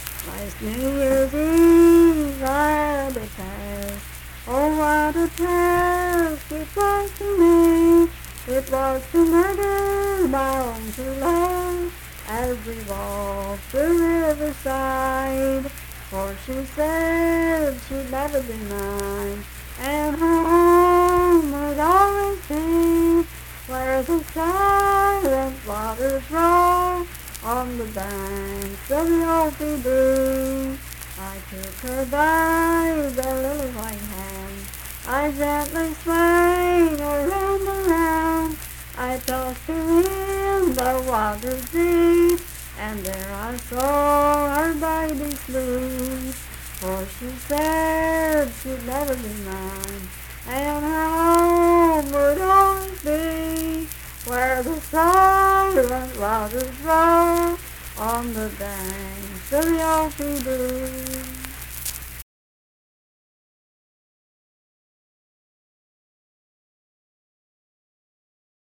Unaccompanied vocal music
Verse-refrain 4(4).
Voice (sung)